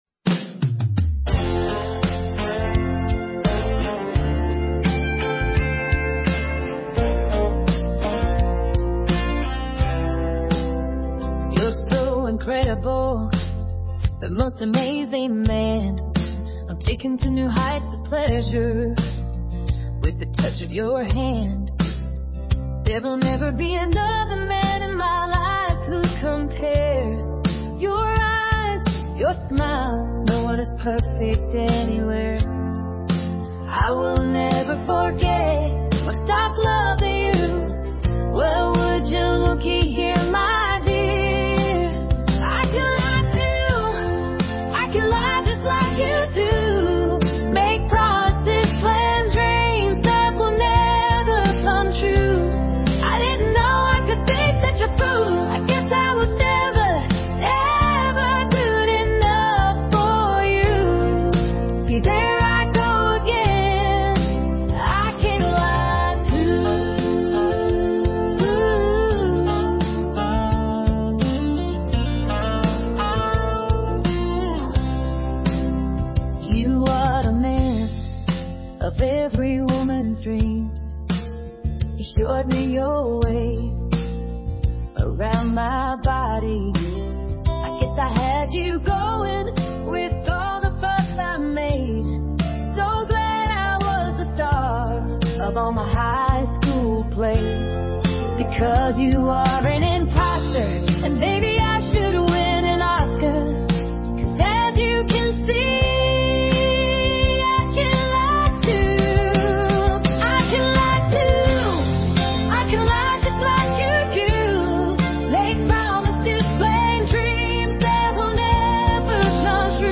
Sarcastic playful song i wrote awhile back and found a good use for AI! I used an app to explain my vision of how these lyrics should be voiced and set to music.
country song